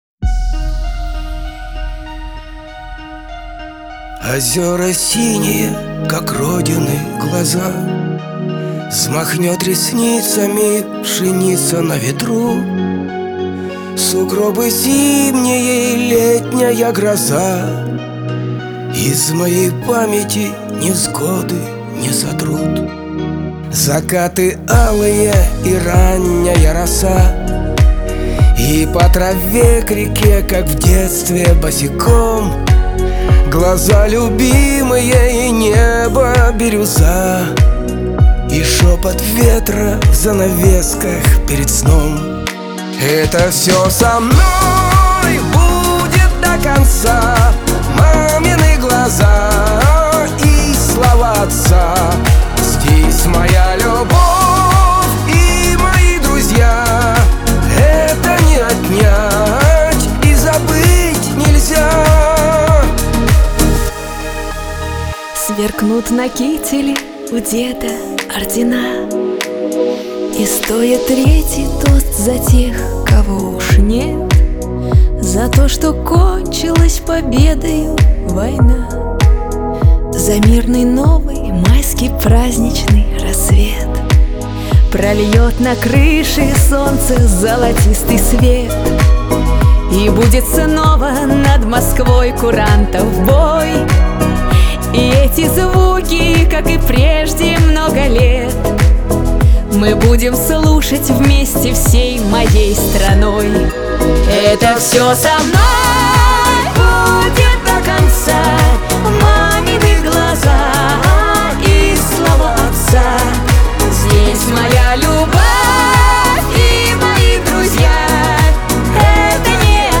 Категория: Шансон песни
русские песни